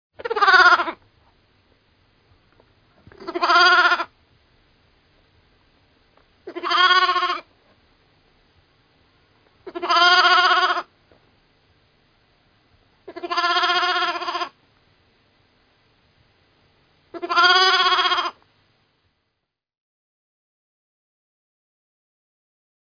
Ягненок плачет без мамы